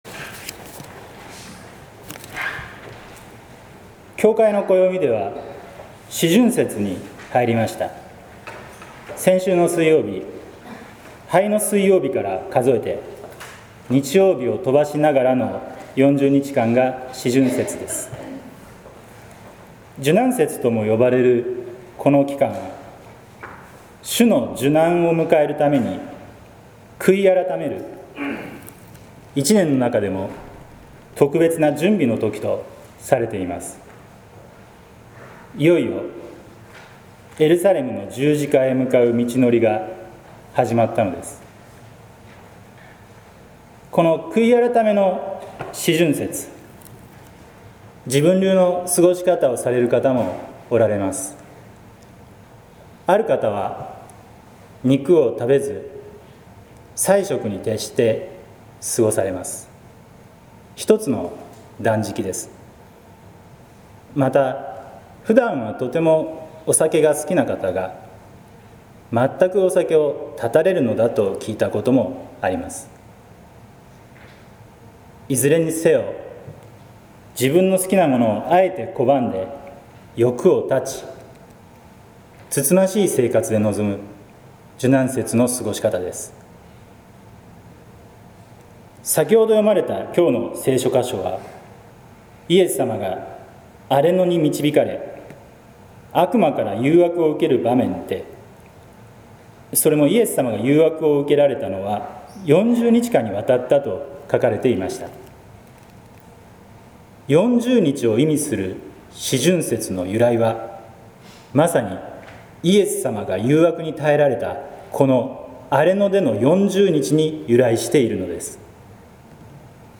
説教「誘惑とたたかう日々」（音声版）